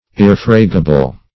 Irrefragable \Ir*ref"ra*ga*ble\, a. [F. irr['e]fragable, L.